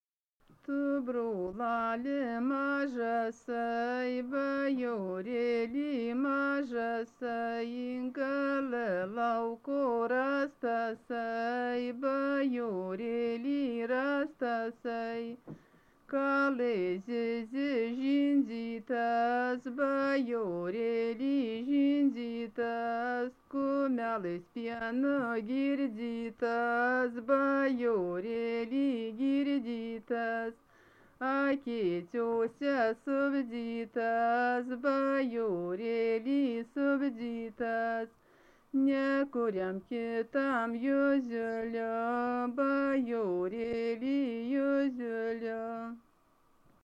Dalykas, tema daina
Erdvinė aprėptis Dargužiai
Atlikimo pubūdis vokalinis